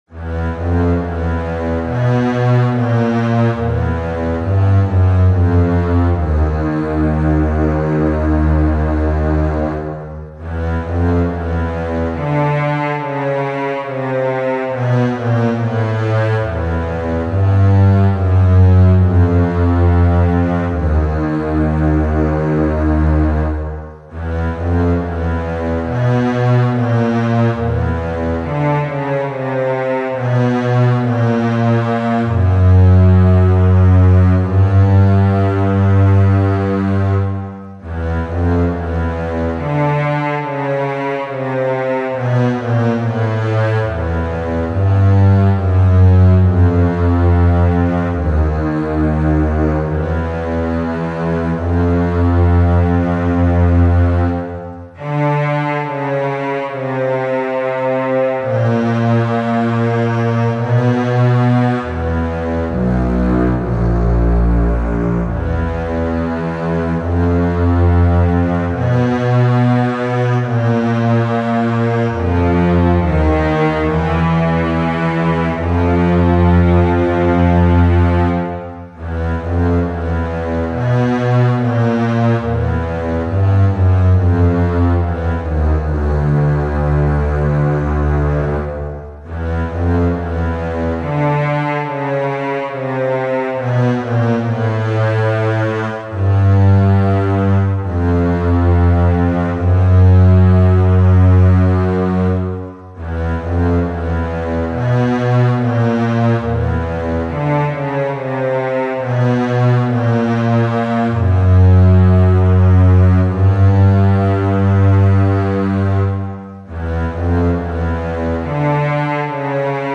【用途/イメージ】　洋館　廃墟　ゴーストハウス　不気味　悲しげ
ストリングスのみのBGM